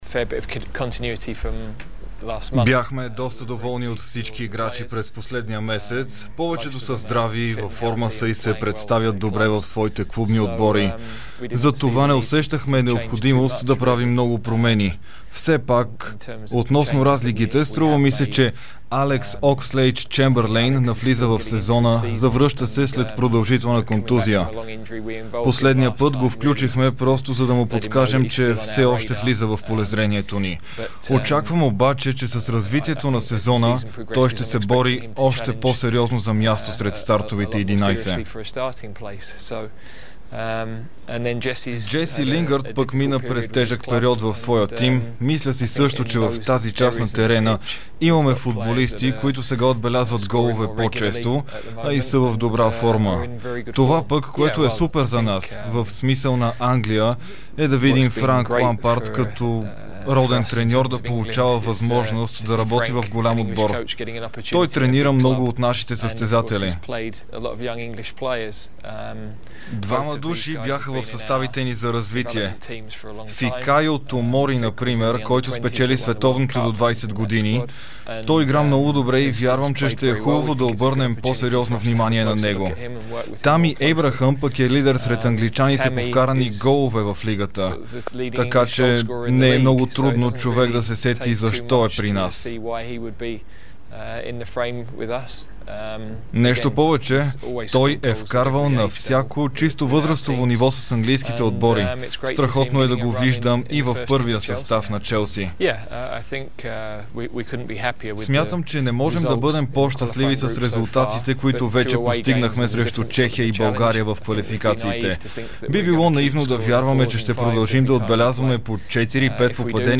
Чуйте цялото изявление на Гарет Сайтгейт в прикачения аудио файл над текста!